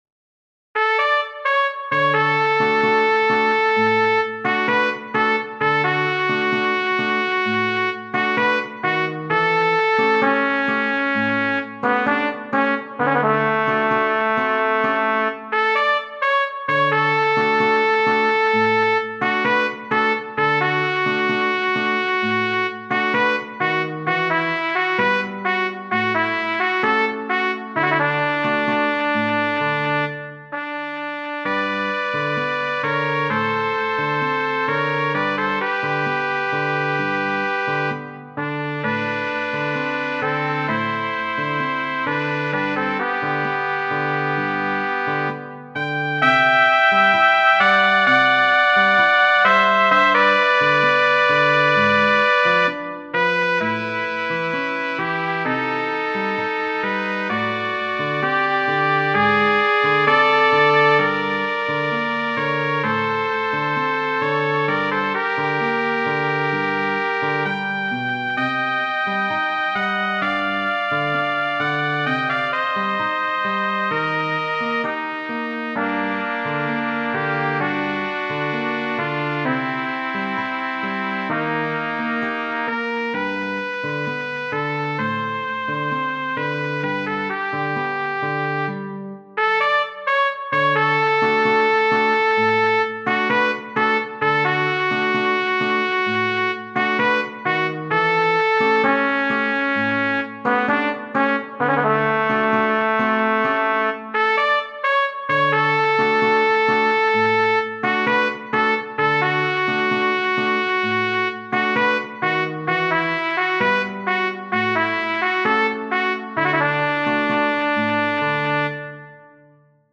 Wood, H. Genere: Ballabili "Royal Empress Tango" è un brano del 1922 scritto dal violinista, direttore d'orchestra e compositore inglese Harry Wood (1868-1939).